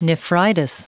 Prononciation du mot nephritis en anglais (fichier audio)
nephritis.wav